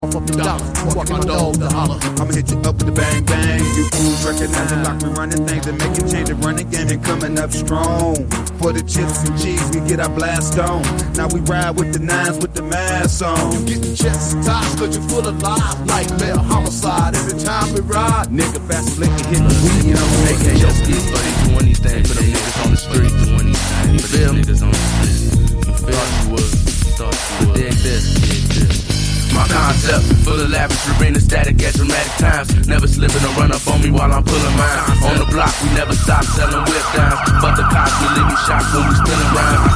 gangsta rap